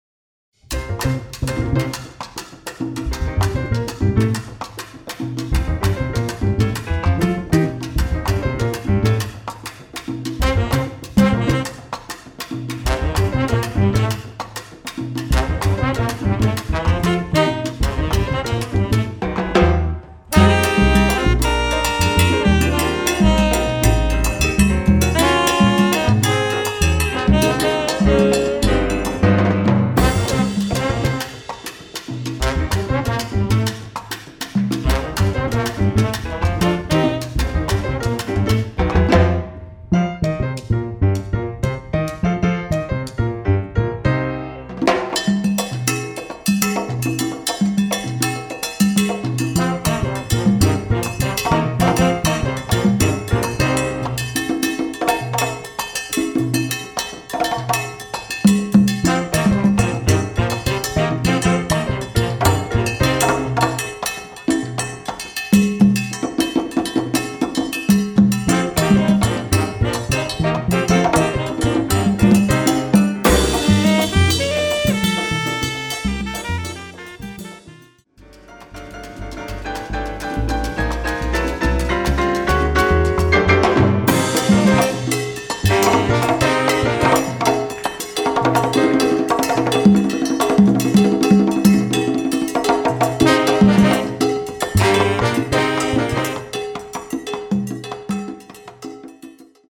Category: combo (sextet)
Style: mambo
Instrumentation: combo (sextet) tenor, trombone, rhythm (4)